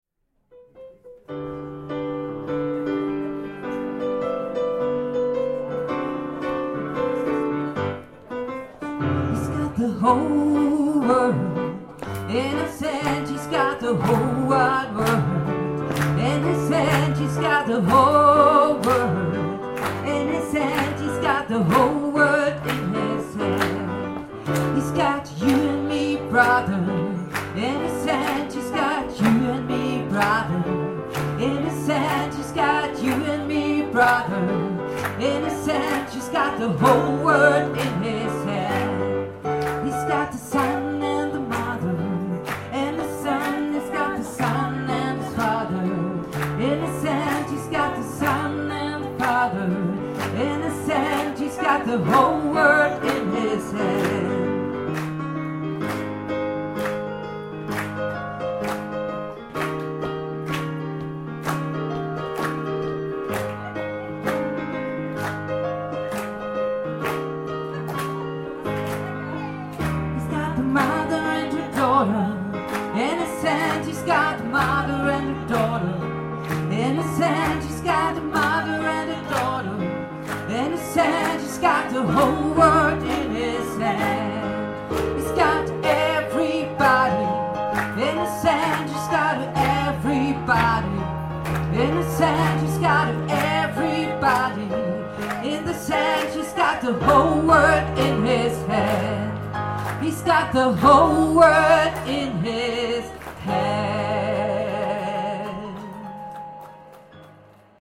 Gospel
Hörprobe (Liveaufnahme)